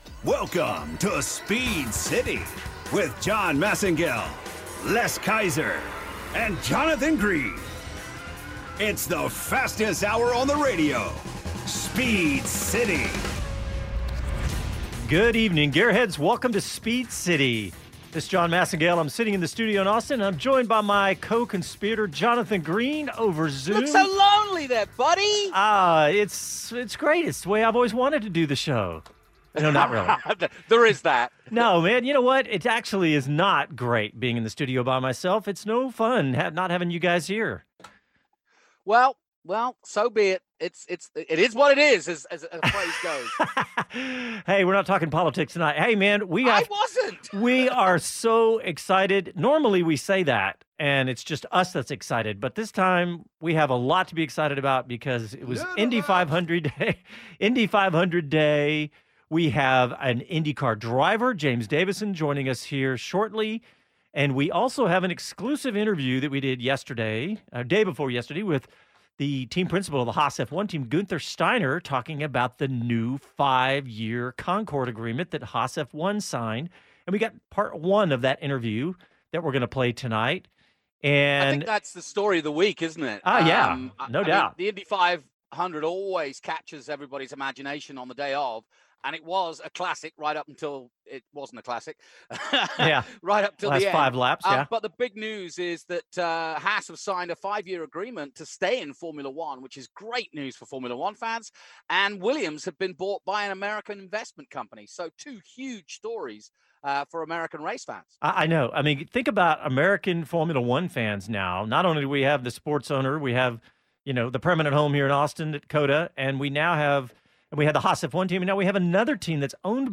James Davison joins us live after his day racing in the 2020 Indy 500. We also have part one of our exclusive interview with Haas F1 Team Principal Guenther Steiner talking about the team’s signing on for 5 more year in Formula 1 after signing the F1 Concorde Agreement. We also get his take on the purchase of Williams F1 by US based Dorilton Capital.